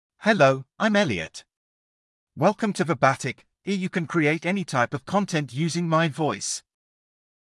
Elliot — Male English (United Kingdom) AI Voice | TTS, Voice Cloning & Video | Verbatik AI
Elliot is a male AI voice for English (United Kingdom).
Voice sample
Elliot delivers clear pronunciation with authentic United Kingdom English intonation, making your content sound professionally produced.